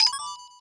Wep Paperplane Signal Sound Effect
wep-paperplane-signal.mp3